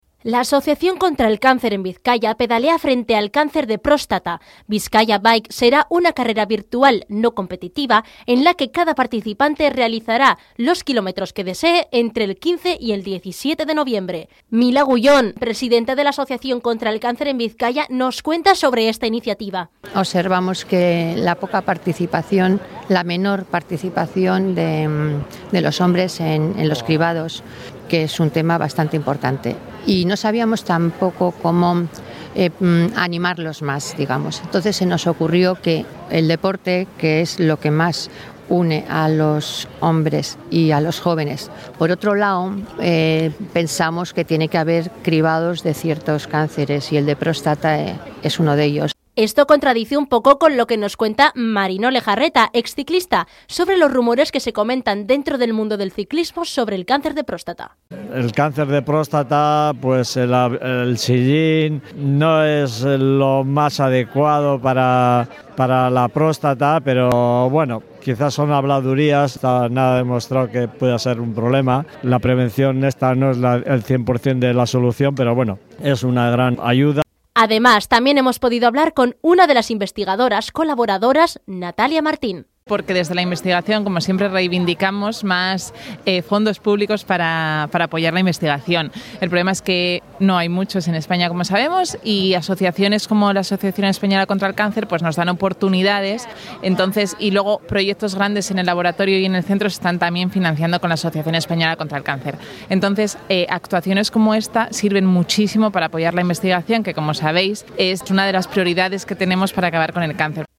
Bizkaia pedalea frente al cáncer de próstata. Hablamos con organizadores y protagonistas de Bizkaia Bike
Esto nos lo comenta Marino Lejarreta, exclicista, que hace hincapié en la prevención, en los exámenes médicos continuos y en todo lo que se necesite para ralentizar los síntomas de esta enfermedad tan común pero tan mortal, a partes iguales.